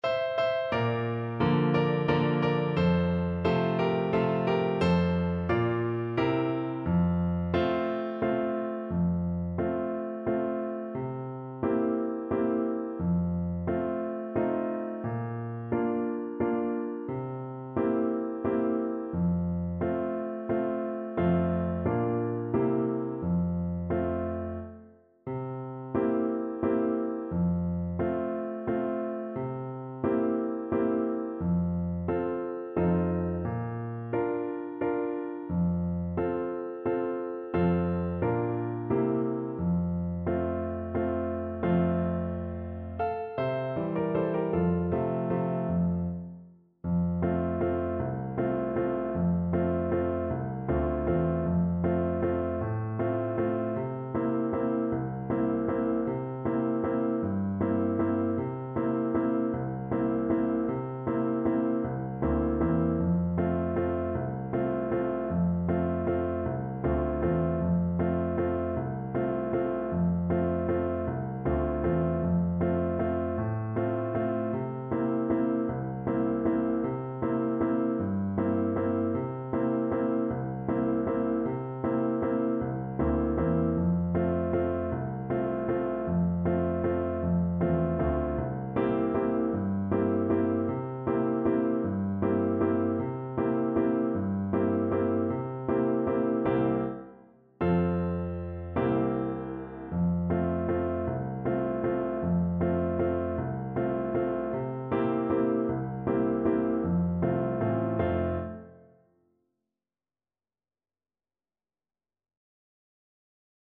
World North America Mexico
3/4 (View more 3/4 Music)
Slow =c.88
Traditional (View more Traditional Flute Music)